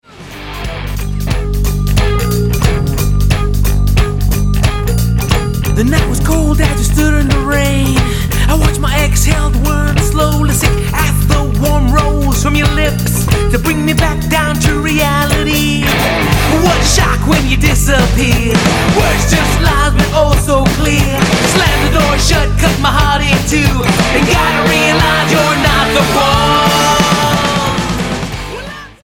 Christian punk
2011 Rock EP